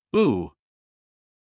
母音/uː/のみの発音
母音Long-uのみの音.mp3